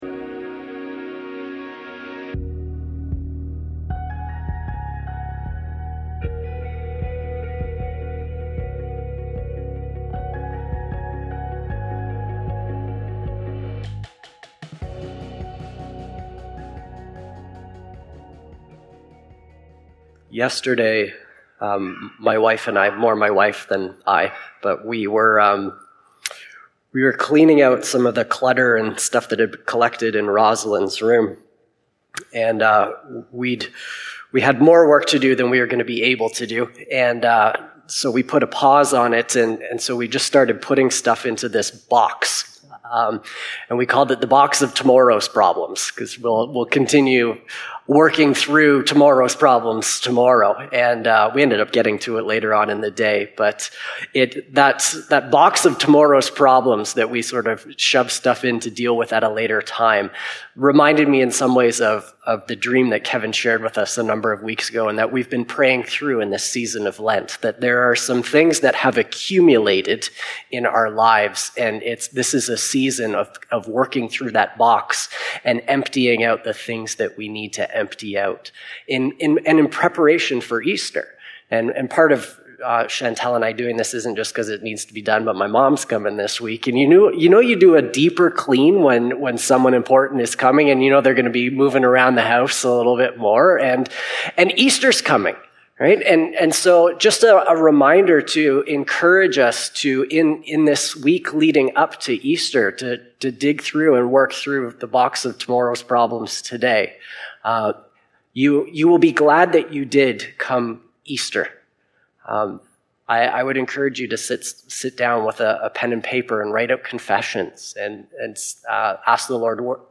Sermons | Unity Baptist Church